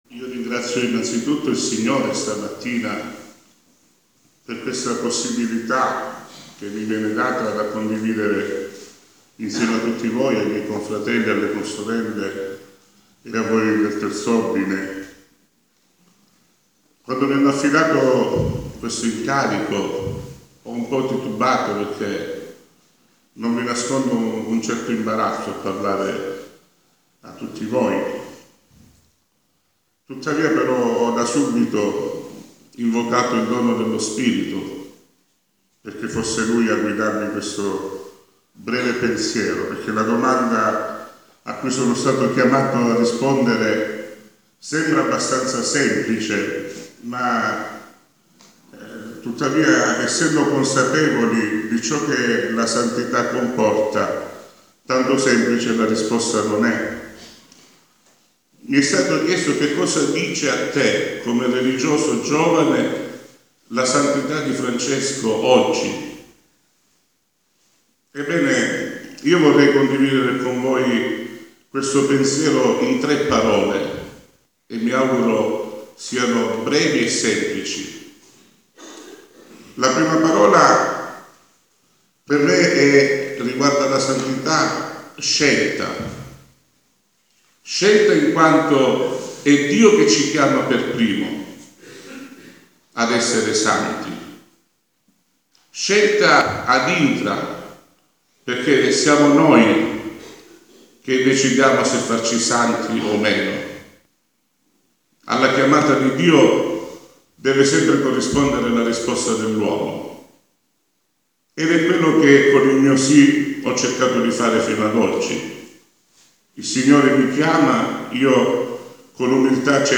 Momenti forti di questa festa, sono state le tre testimonianze date rispettivamente da un religioso e sacerdote,